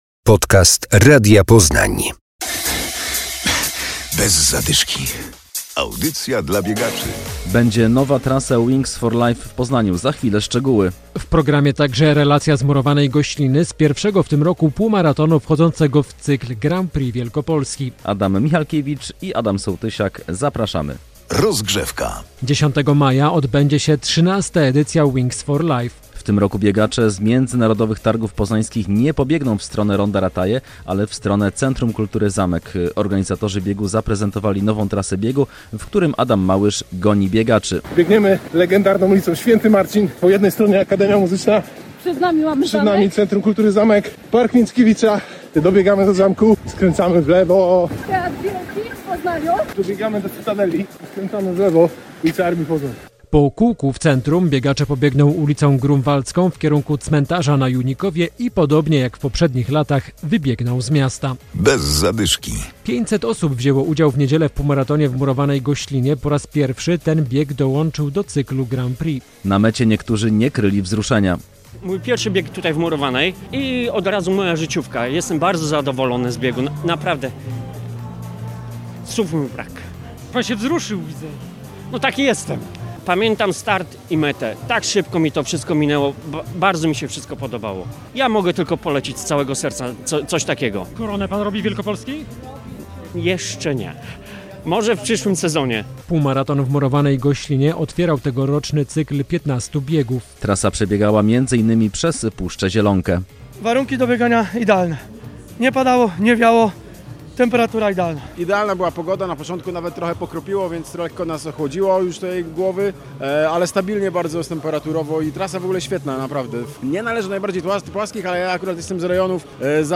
Relacja z półmaratonu w Murowanej Goślinie.